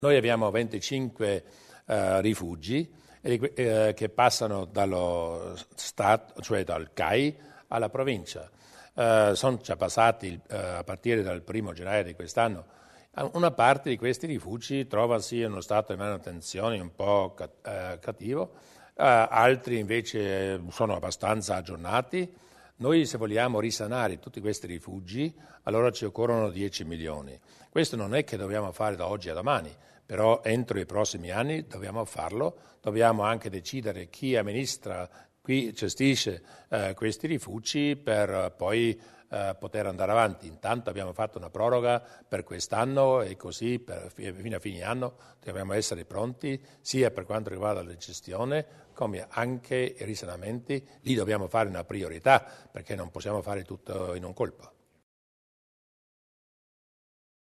Il Presidente sulle nuove competenze per i rifugi alpini